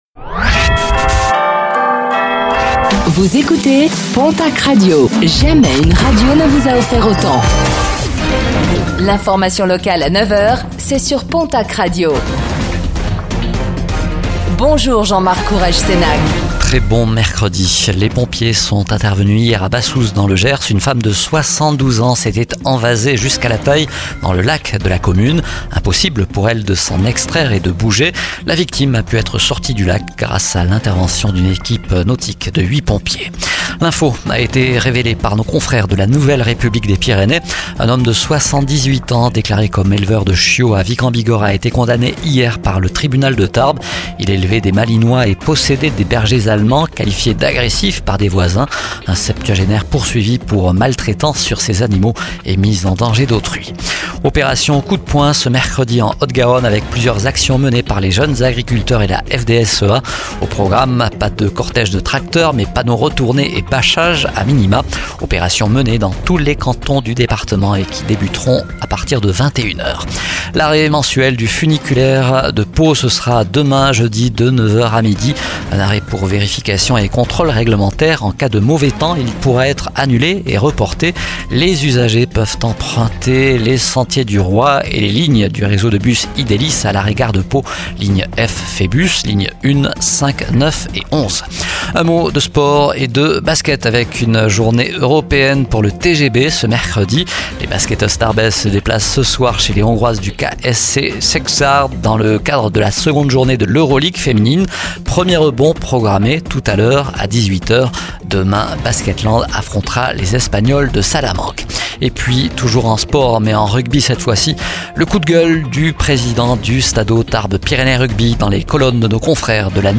09:05 Écouter le podcast Télécharger le podcast Réécoutez le flash d'information locale de ce mercredi 16 octobre 2024